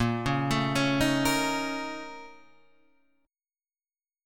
A#mM7bb5 chord {6 4 7 6 4 6} chord